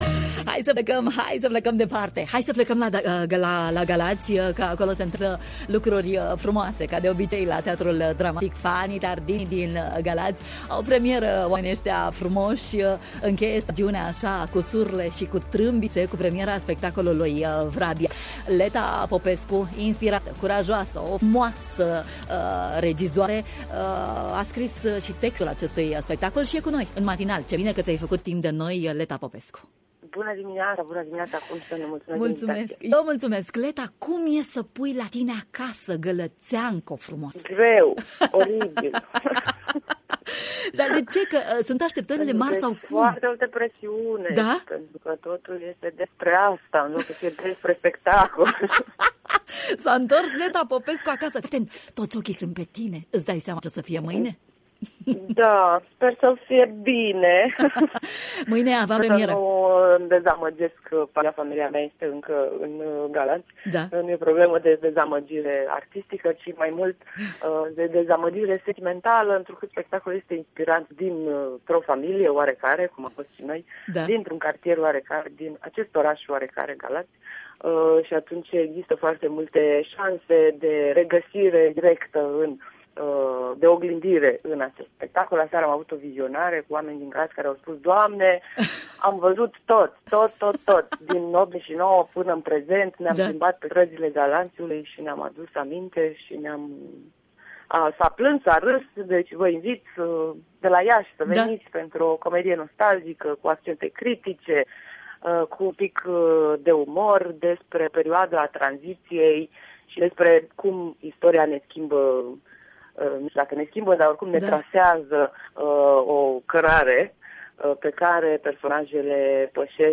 în direct în matinalul de la Radio România Iaşi: